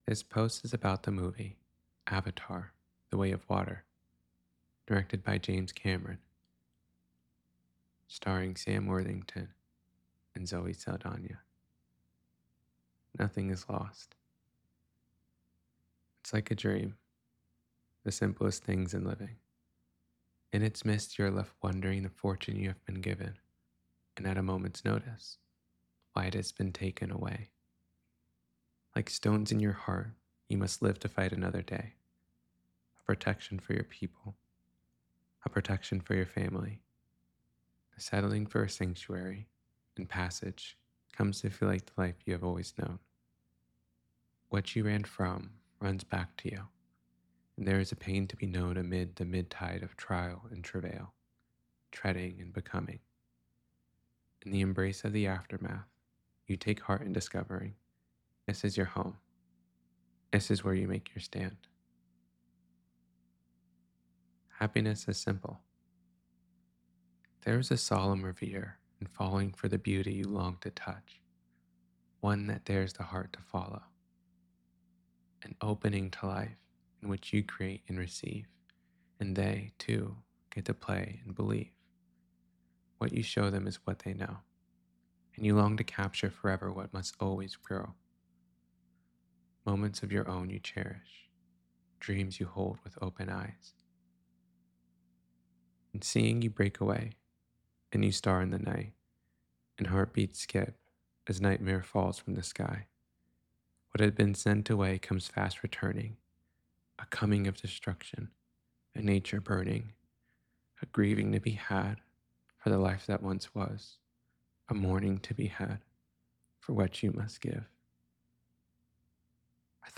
avatar-the-way-of-water-to-know-a-story-reading.mp3